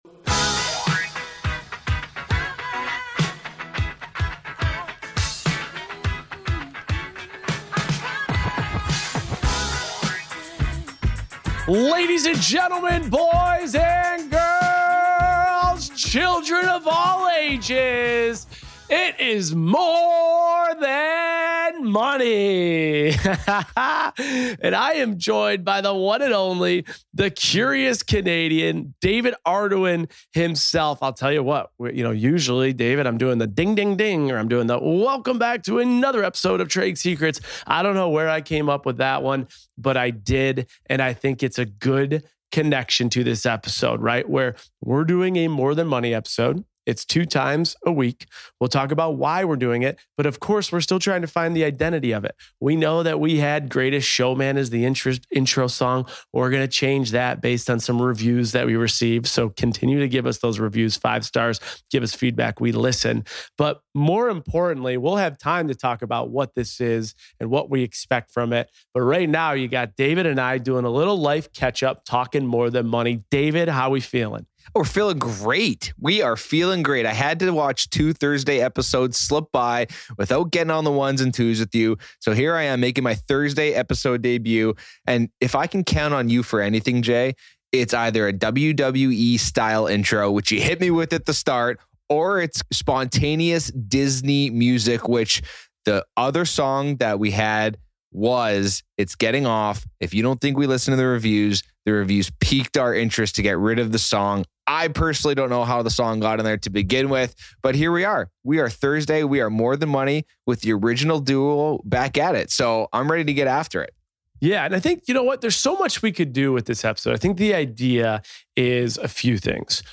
Host: Jason Tartick Co-Host